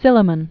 (sĭlə-mən), Benjamin 1779-1864.